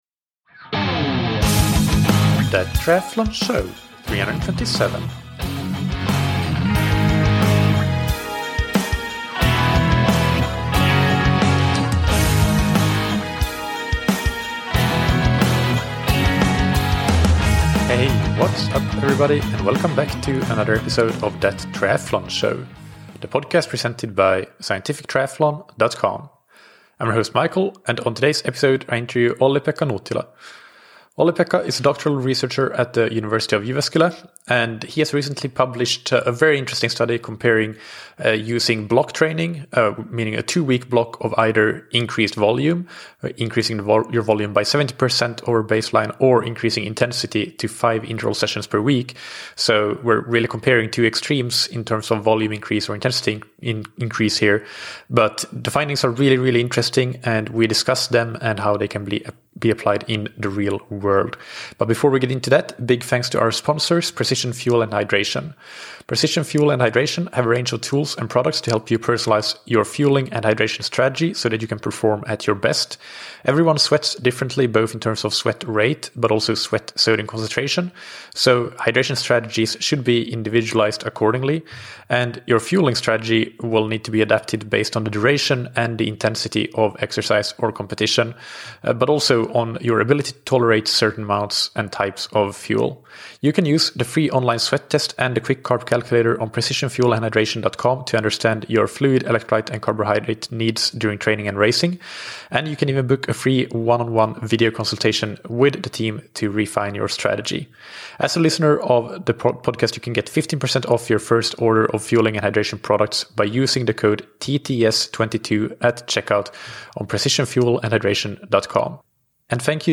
In this interview we discuss his recent study comparing two-week blocks of either increased volume (70% increase over baseline) or increased intensity (5 interval sessions per week), and how the findings can be applied in the real world.